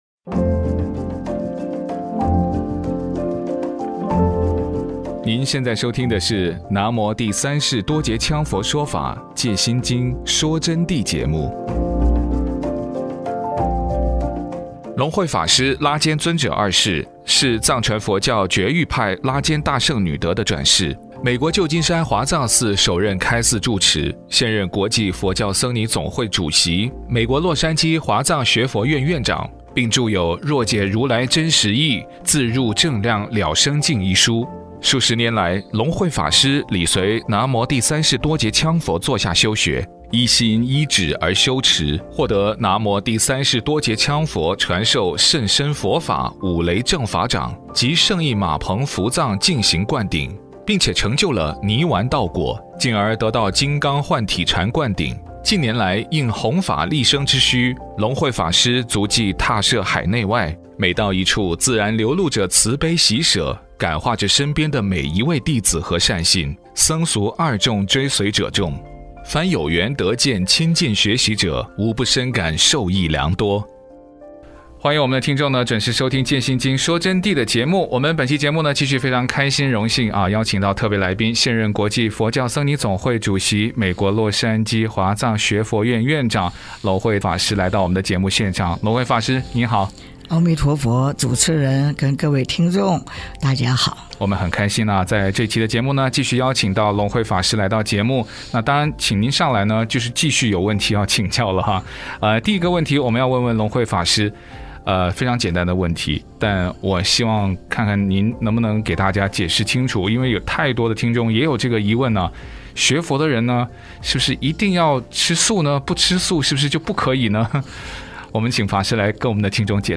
佛弟子访谈（三十二）浅谈学佛吃素、不杀生、四无量心、佛法在世间不离世间觉、八风吹不动和对佛陀觉量概念的理解